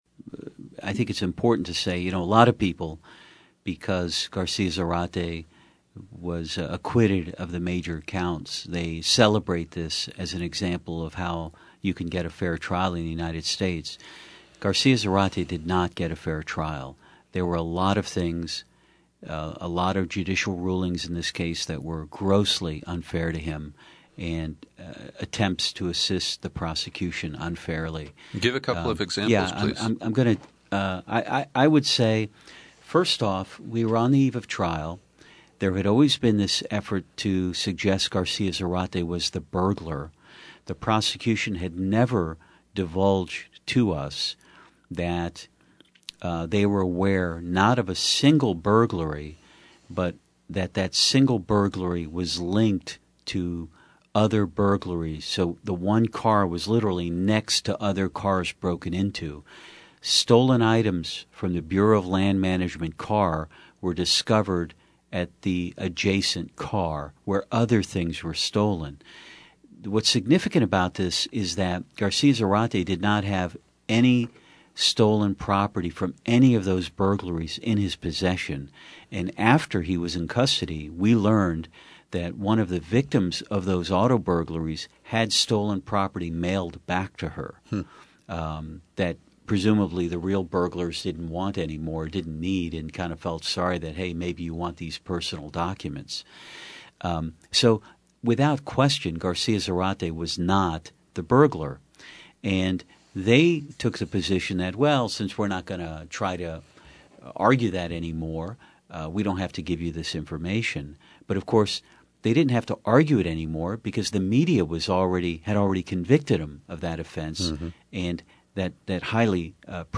In-Depth Interview: Public Defenders Tell the Full Story of Politically Charged Case of Immigrant Accused of Killing Kate Steinle